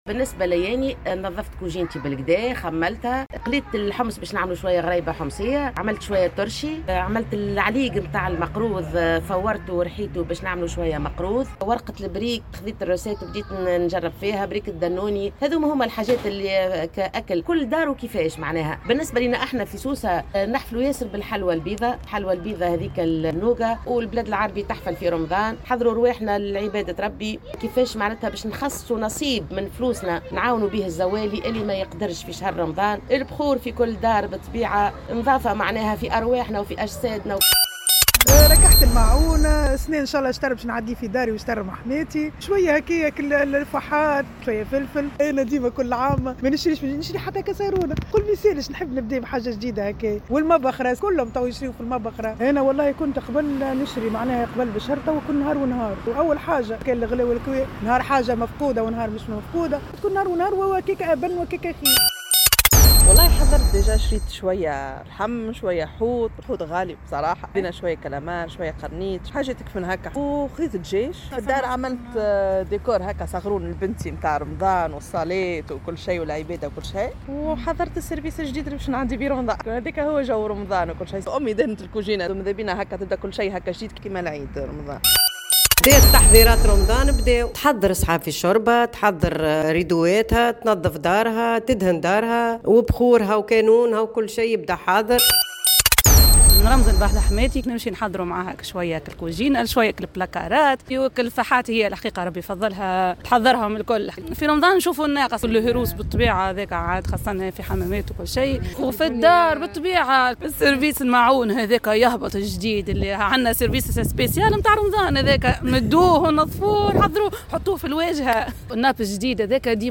وقالت عدد من النساء في تصريحات متطابقة لـ"الجوهرة أف أم"، إن شهر الصيام هو شهر للعبادة والتقرّب من الله وإحياء صلة الرحم من خلال تكثيف الزيارات العائلية والجلوس على مائدة الإفطار مع الأهل والأقارب.